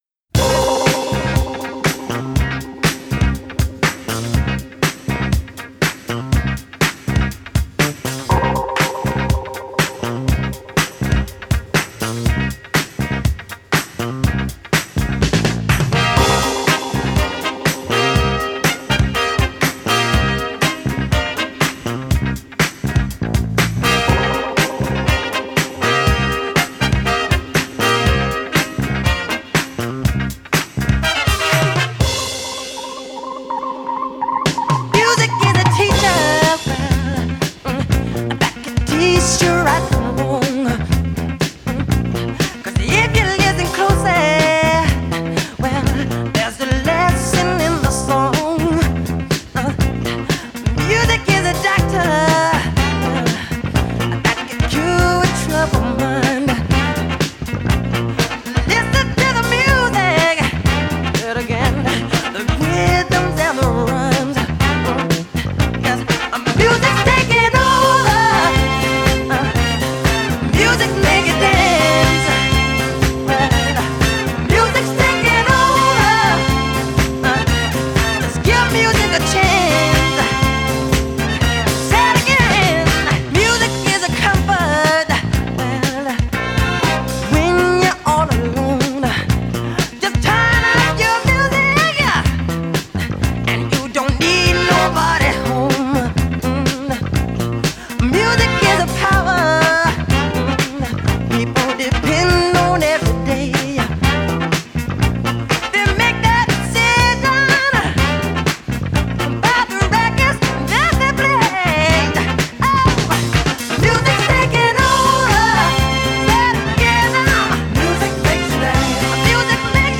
Funk / Soul / Disco